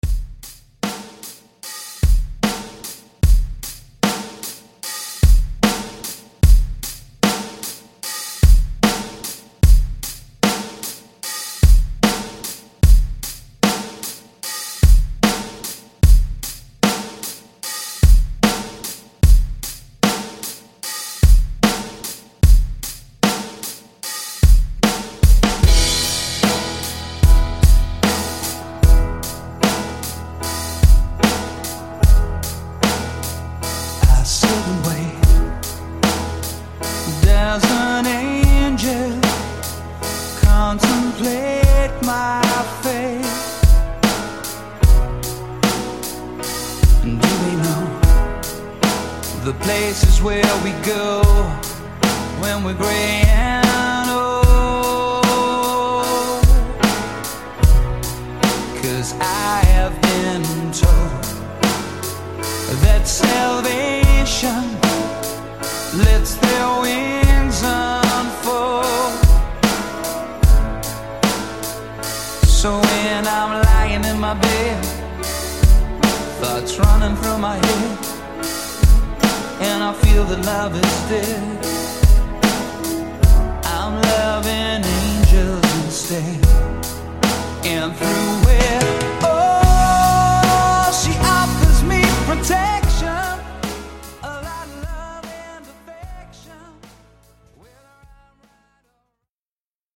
Genres: DUBSTEP , RE-DRUM , TOP40
Clean BPM: 150 Time